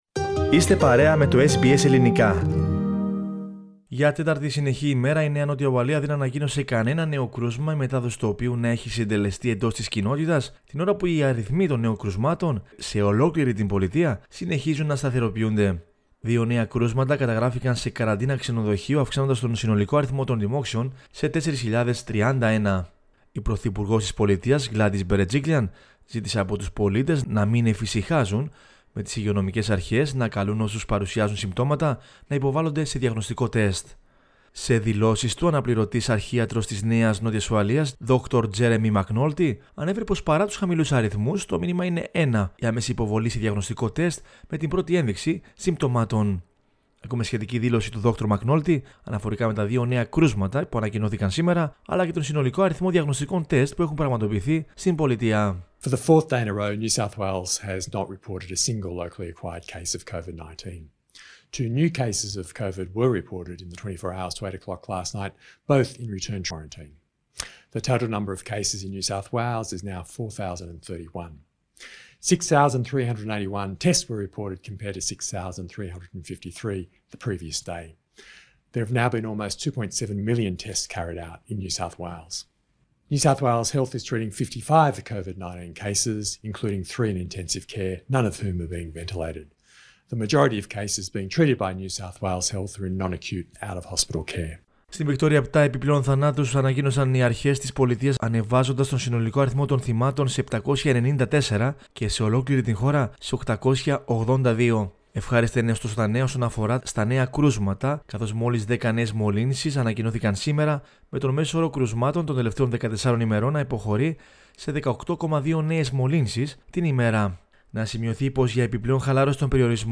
Ακούμε σχετική δήλωση του Δρ McAnulty αναφορικά με τα δύο νέα κρούσματα που ανακοινώθηκαν σήμερα, αλλά και τον συνολικό αριθμό διαγνωστικών τεστ που έχουν πραγματοποιηθεί στην πολιτεία.